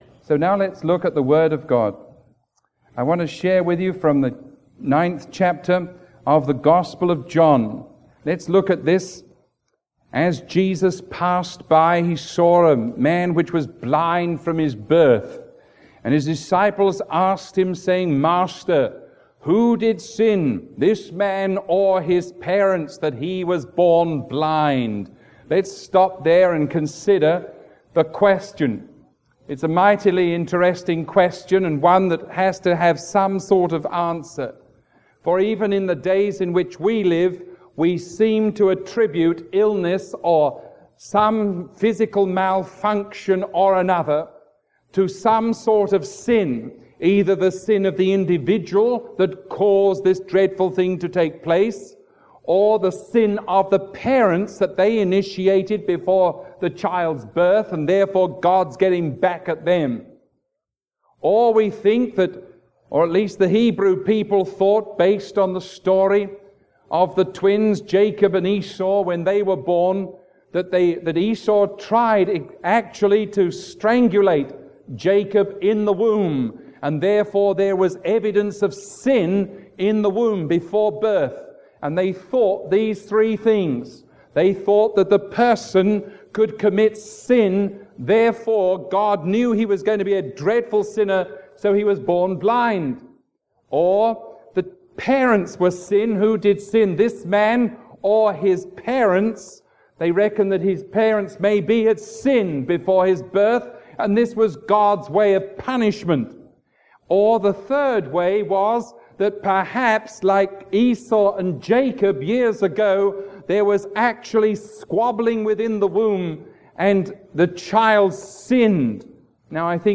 Sermon 0371AB recorded on June 21